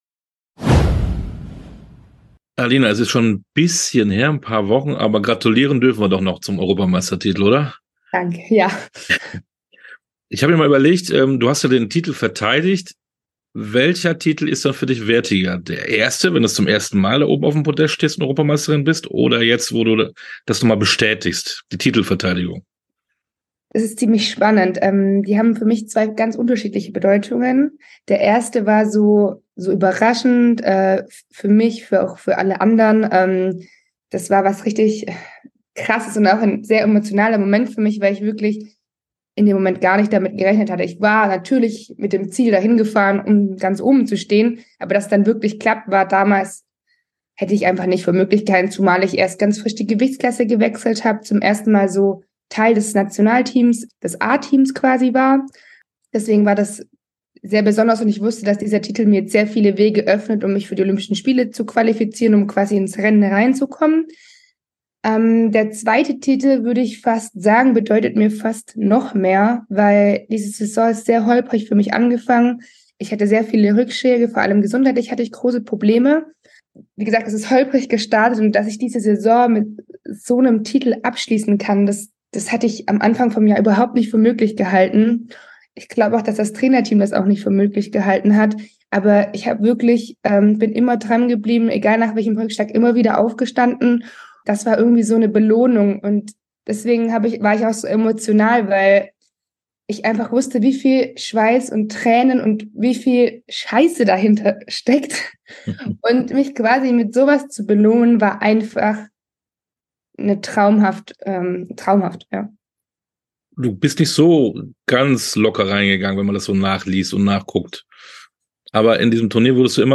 Sportstunde - Interviews in voller Länge